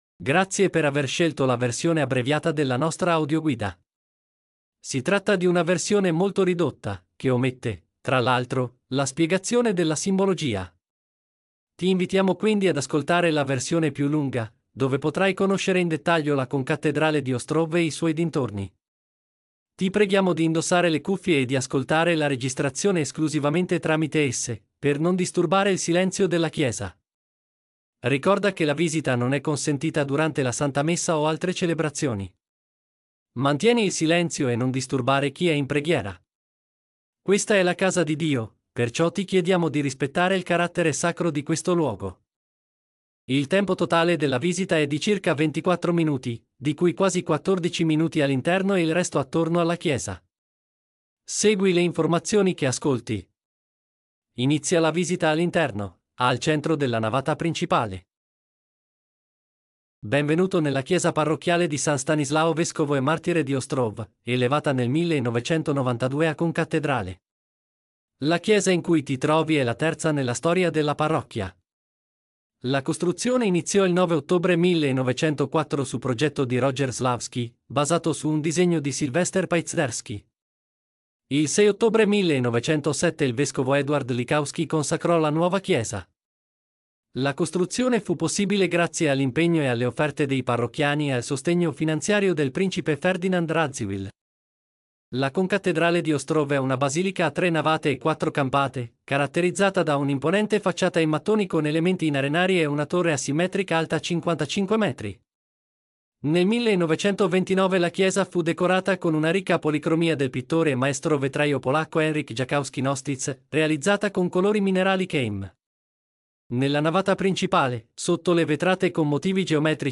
[IT] Audioguida – Parafia Konkatedralna Świętego Stanisława Biskupa Męczennika w Ostrowie Wielkopolskim